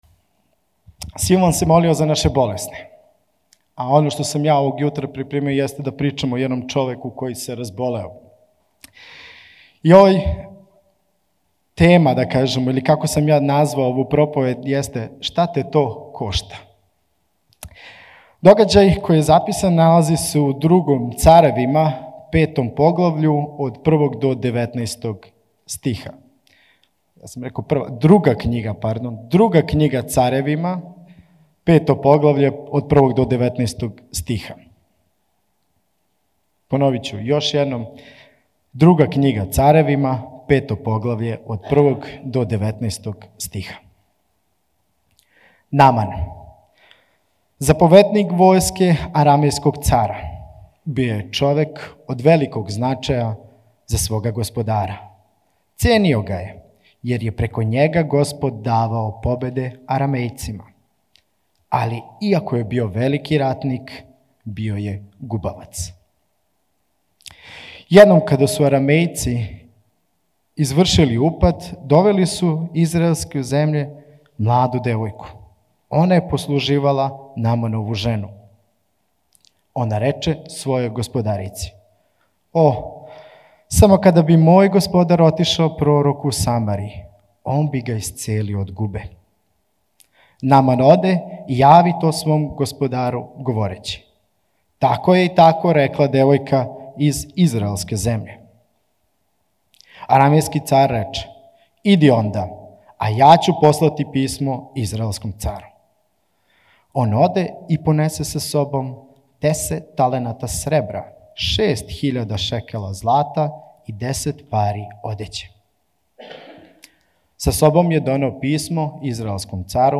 Аудио проповеди